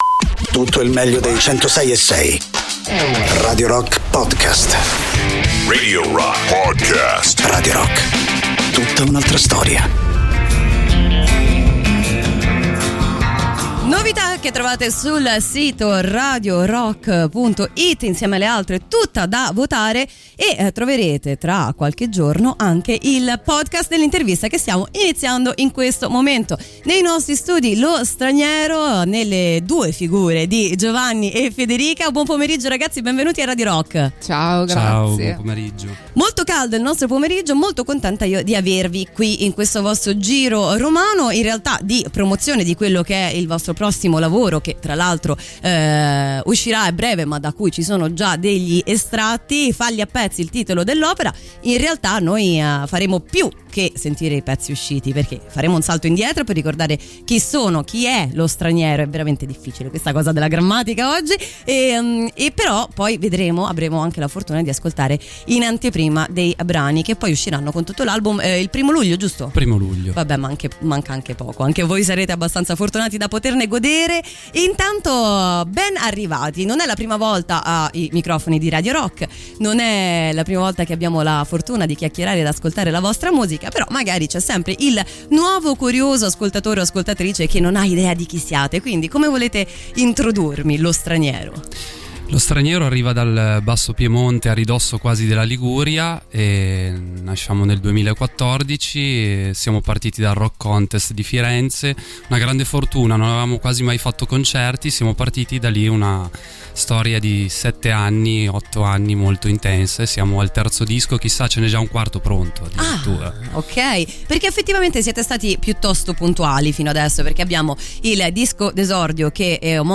Intervista: Lo Straniero (18-06-22)